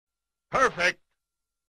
Perfect-Street-Fighter-Sound-Effect.mp3